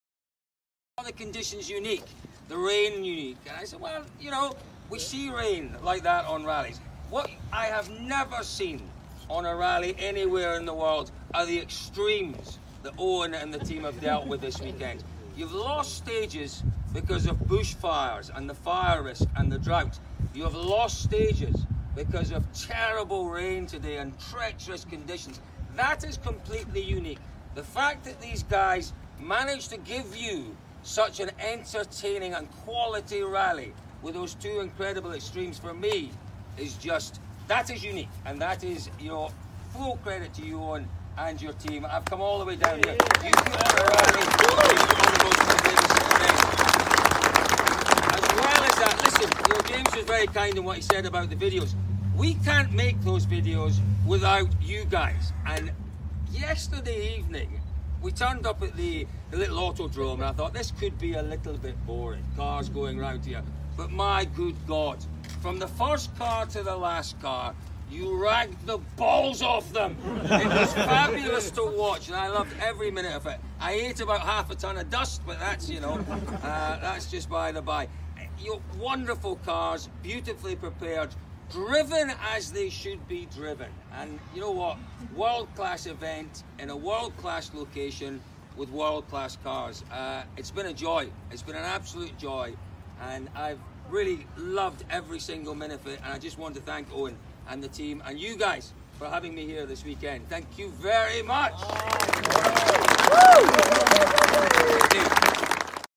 Finish Speech [ Not broadcast quality ] OUT CUE
(Applause) CLICK HERE ( 1 min 46 secs) Alpine Rally NEWS RELEASE page HERE Alpine Rally RADIO LINKS page HERE Crammond Media Home HERE © Crammond Media2019